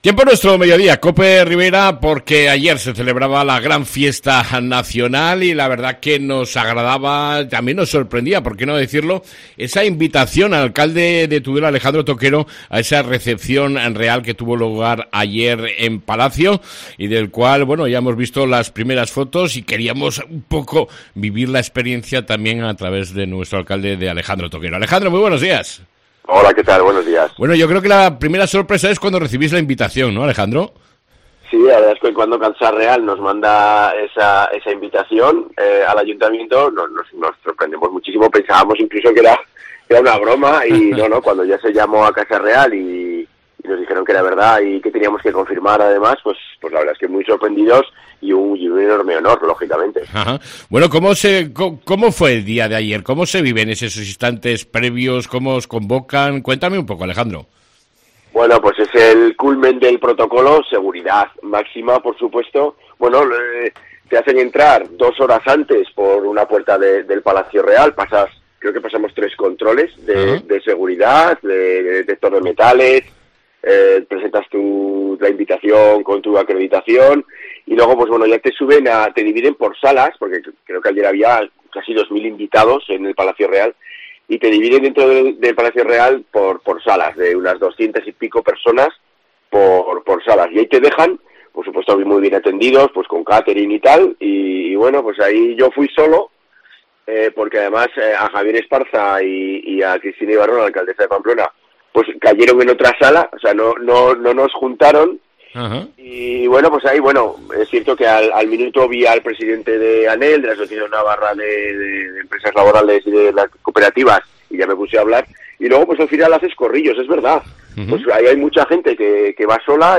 ENTREVISTA CON EL ALCALDE DE TUDELA, ALEJANDRO TOQUERO